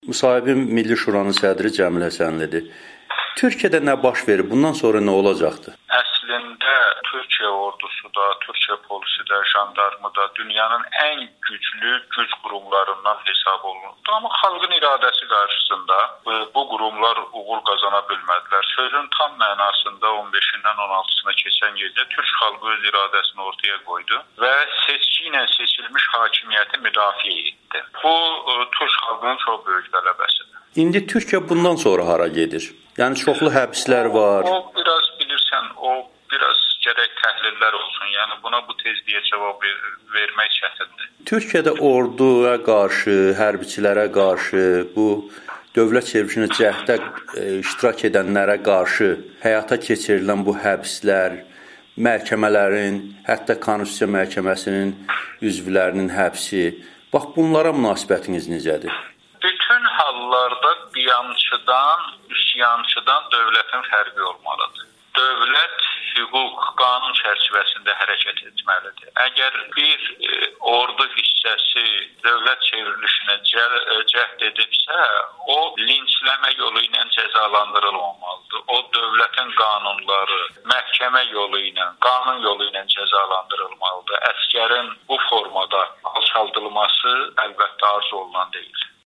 Azərbaycan siyasətçilərinin Türkiyə olayları ilə bağlı Amerikanın Səsinə müsahibələri